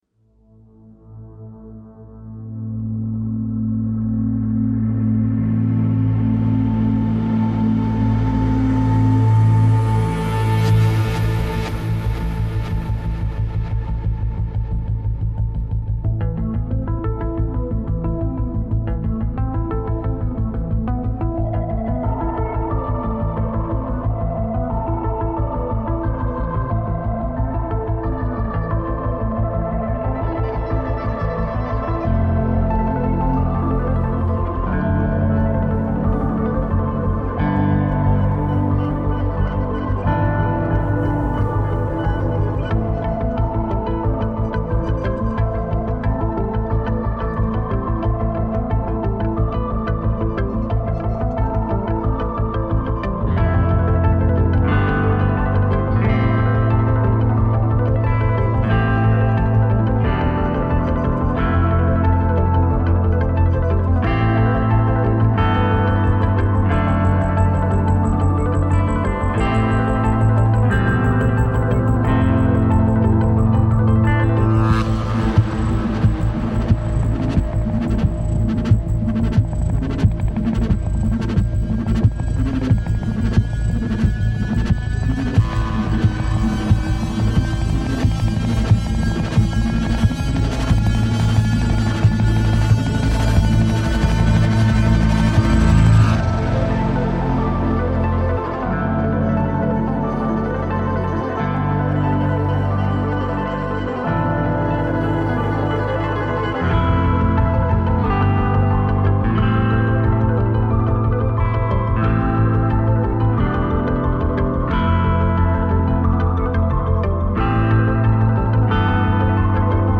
这些环境，感性，抽象，电影般的吉他声音和生动的音乐质感将为您的音乐增添色彩和深度。
虹膜/圆圈膨胀得越大，效果越强烈。